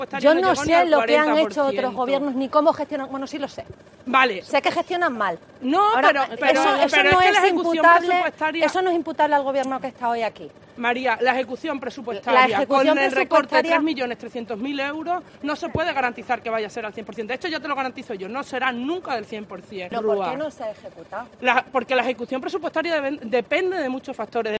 La presidenta de la Junta de Extremadura se ha acercado a hablar con los miembros de la Coordinadora que se manifestaban a las puertas de la Asamblea